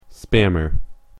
• : -æmə(ɹ)